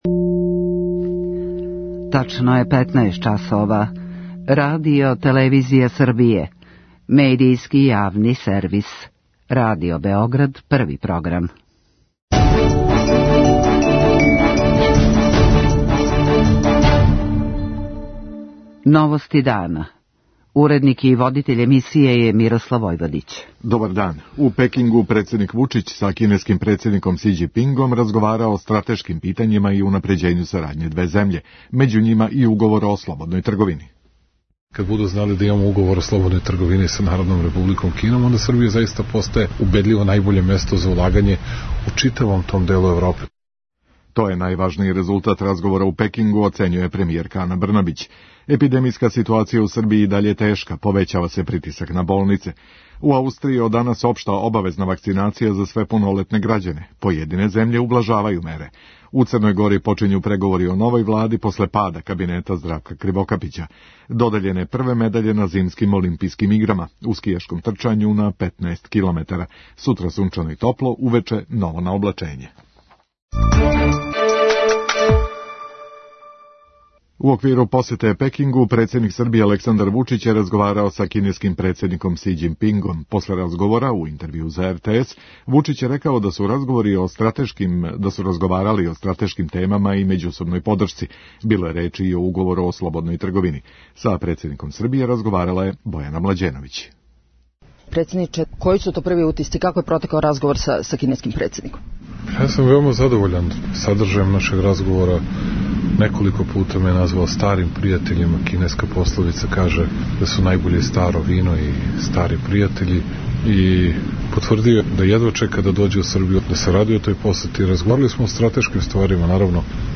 Србија и Кина немају ниједно отворено питање, већ сарађују у бројним областима, истакао је председник Александар Вучић после састанка са кинеским председником Си Ђинпингом у Пекингу. У интервјуу за РТС, Вучић је рекао да је са кинеским председником разговарао о стратешким темама, међусобној подршци и очувању територијалног интегритета у међународним институцијама и организацијама.